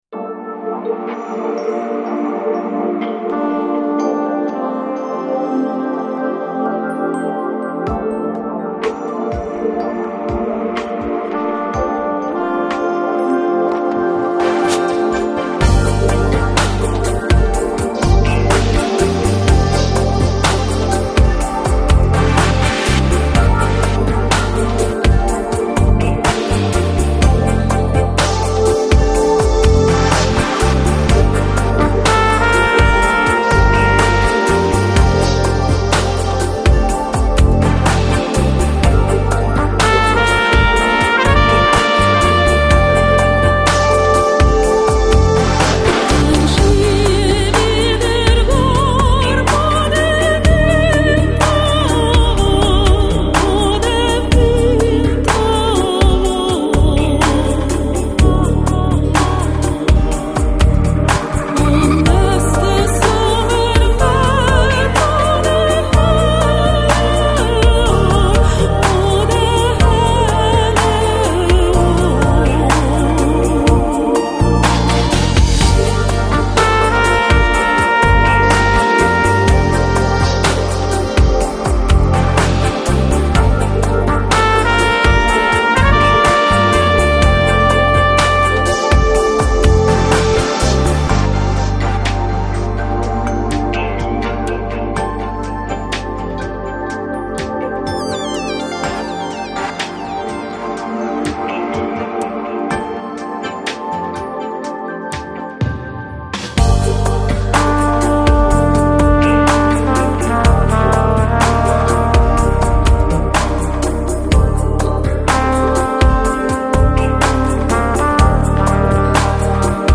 Electronic, Chill Out, Downtempo, Balearic, Lounge